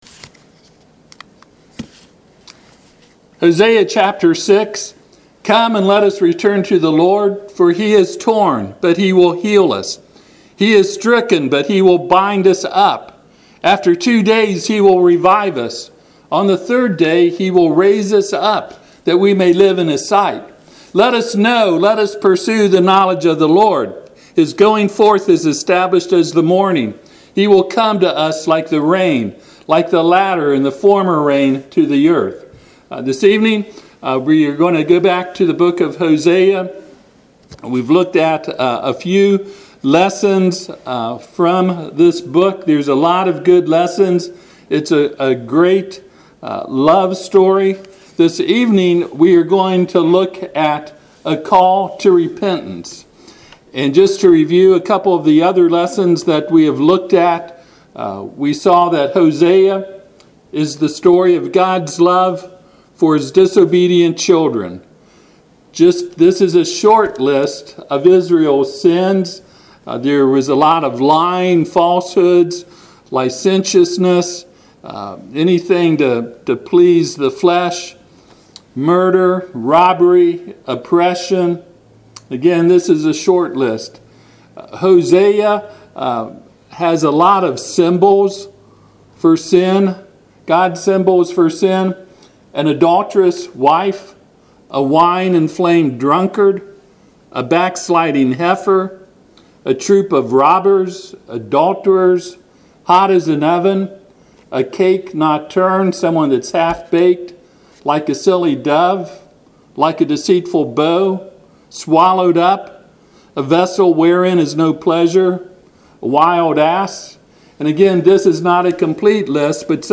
Passage: Hosea 6:1-3 Service Type: Sunday PM